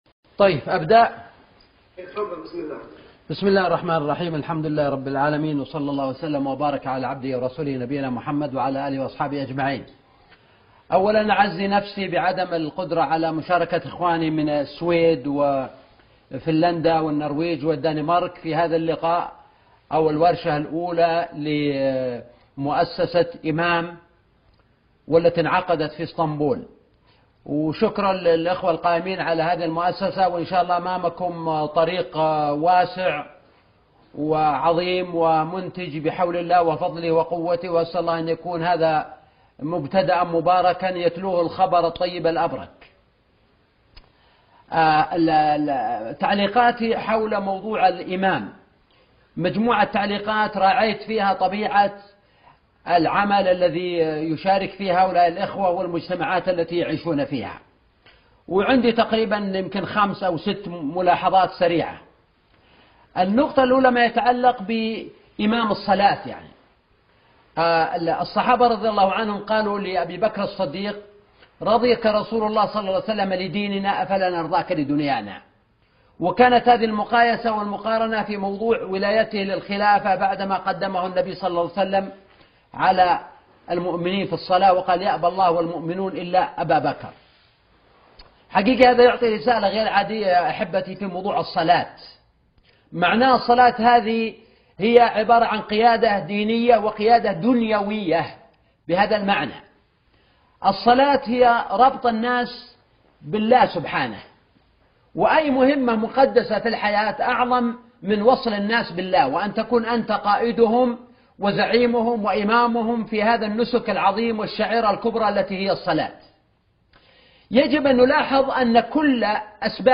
دور الإمام فى أوربا -محاضرة - الشيخ سلمان العودة